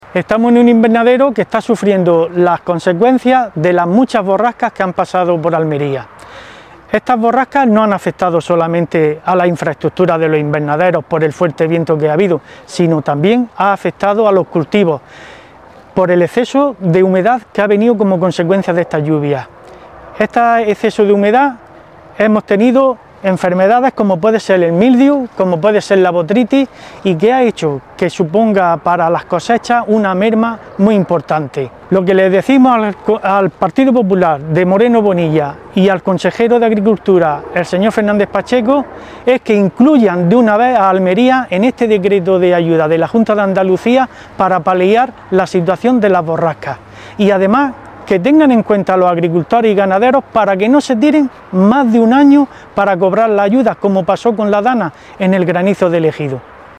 Fernández Mañas ha realizado estas declaraciones desde un invernadero afectado por los temporales.